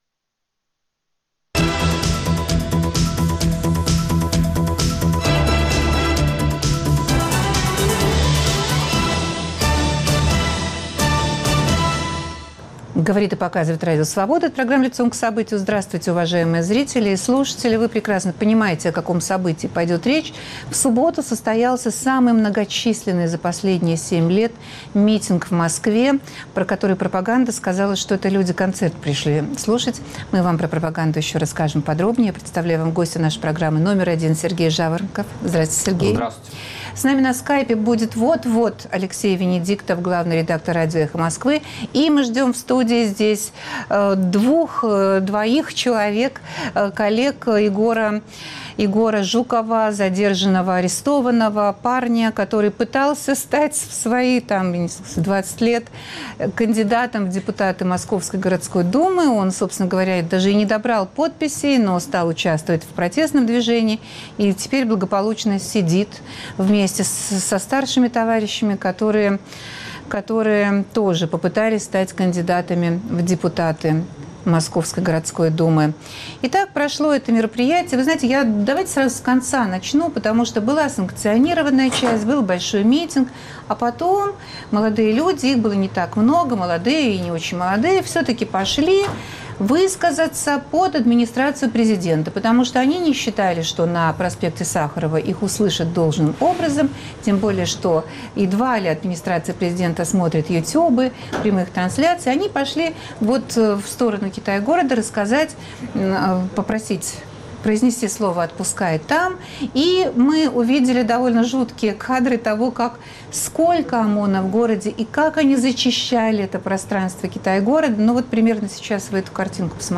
Новый большой митинг в Москве и новые задержания в ходе "гуляний". Пропаганда больше не может не замечать протестных акций, но пытается подавать их как хаотичное брожение случайных людей с неясными целями но ясным, конечно же иностранным, финансированием. Чему и кому поверят россияне? В разговоре участвуют